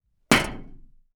Metal_25.wav